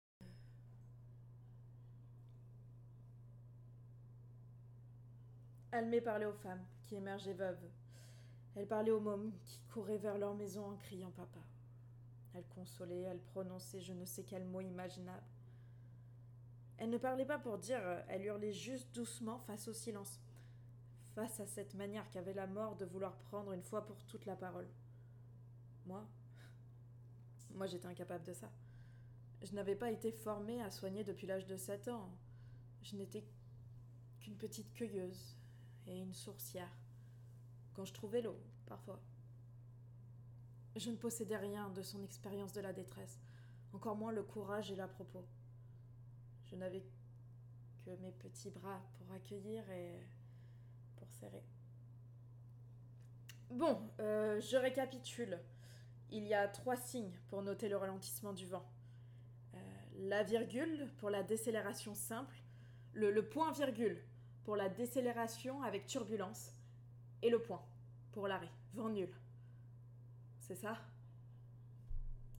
Lecture hordre du contre vent
5 - 37 ans - Mezzo-soprano